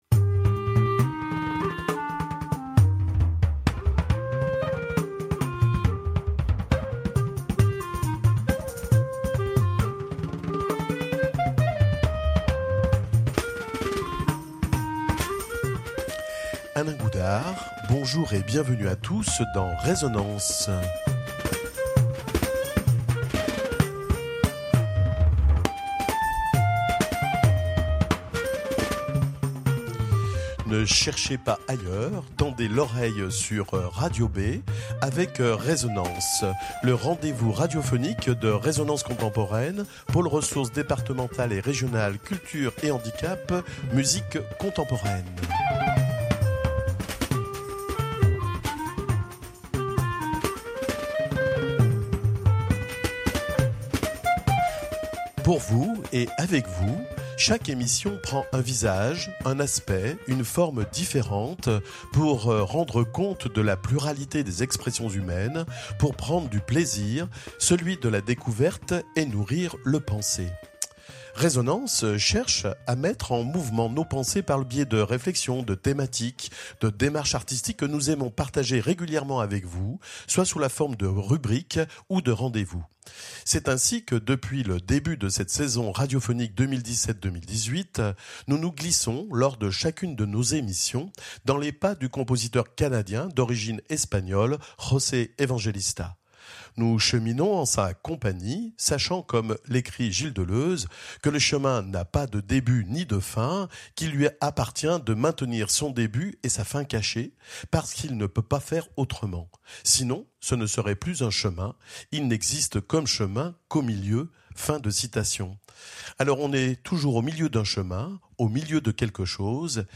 Extraits musicaux